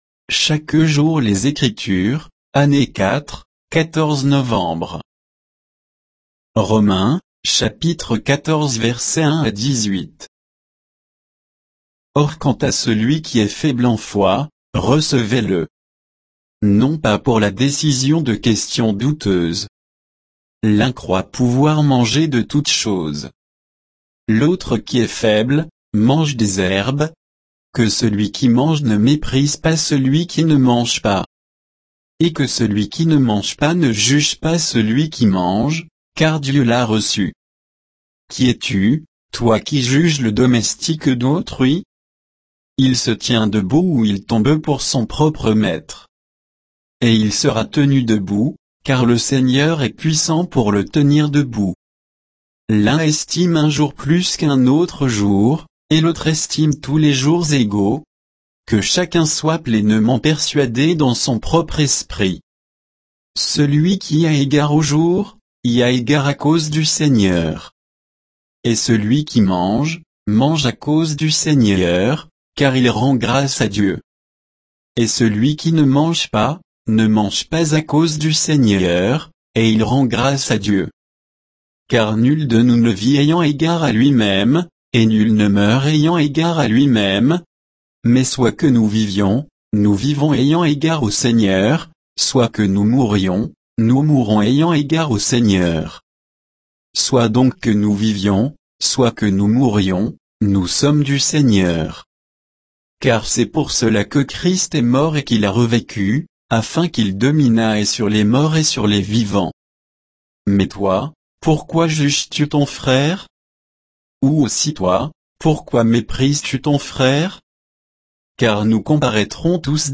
Méditation quoditienne de Chaque jour les Écritures sur Romains 14, 1 à 18